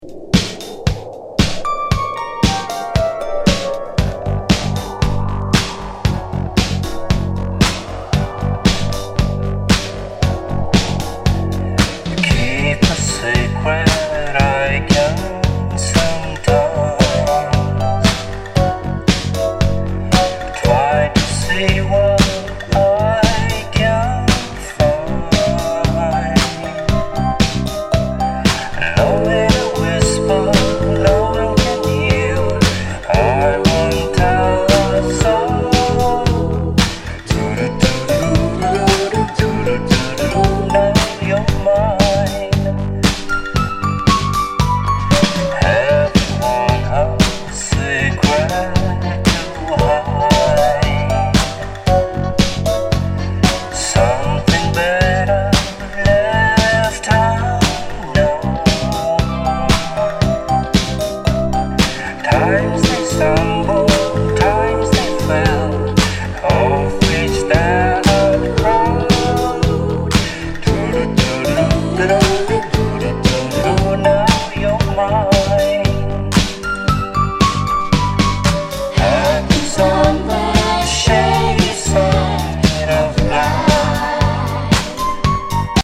グッドタイミー・ポピュラー・ボーカル・エレクトリック・アップデートなスバラシ・ムード。